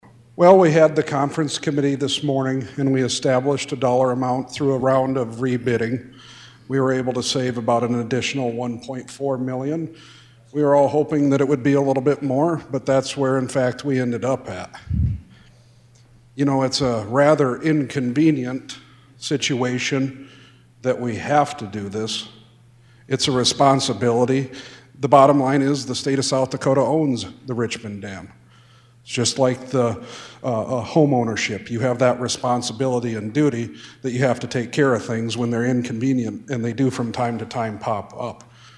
Senator Mark Lapka of Leola was also on the committee and talks about his support on the Senate floor.